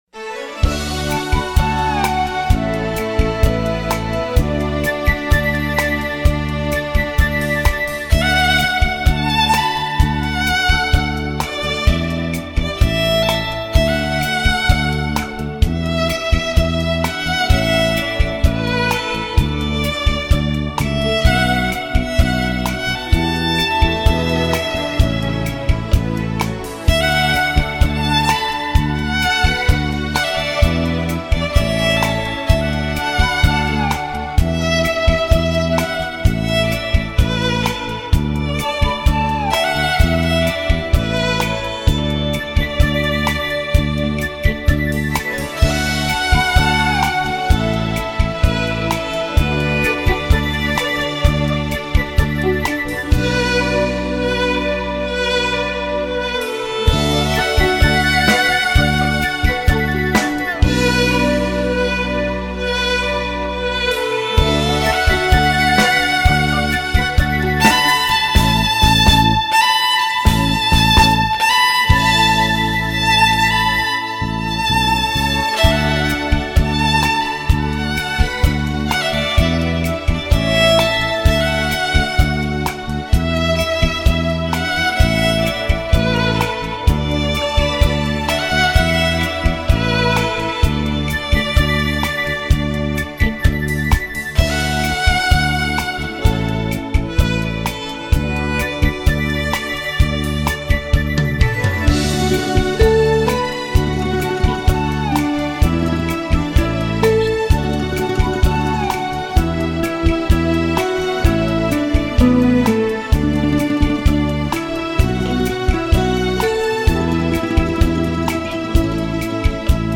벨소리